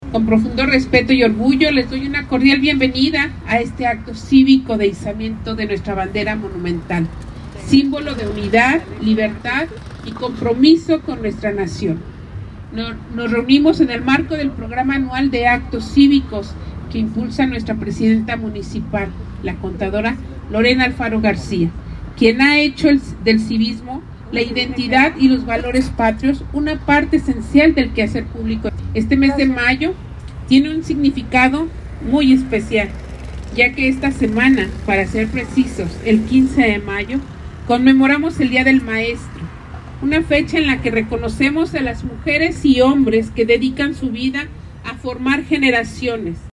Durante este acto cívico autoridades de la Secretaría de la Defensa Nacional (Sedena) realizaron el izamiento de la bandera nacional y rindieron honores al lábaro patrio.